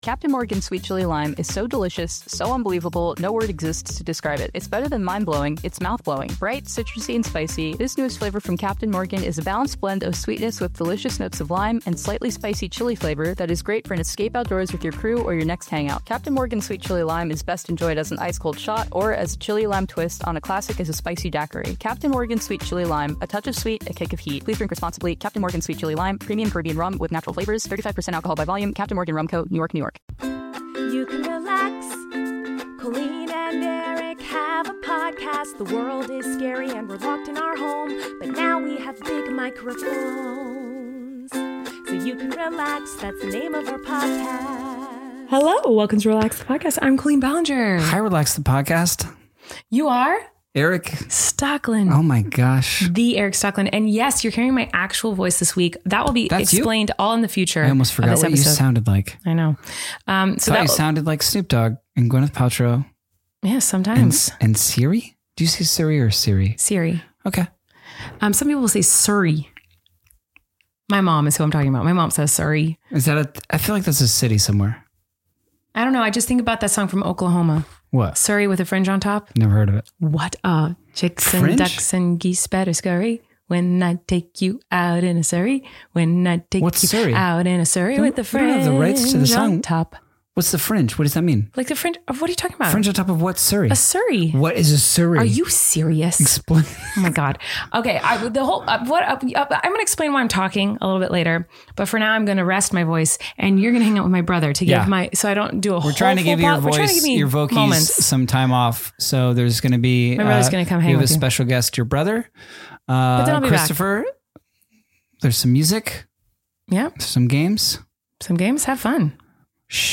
Also there are many musical performances.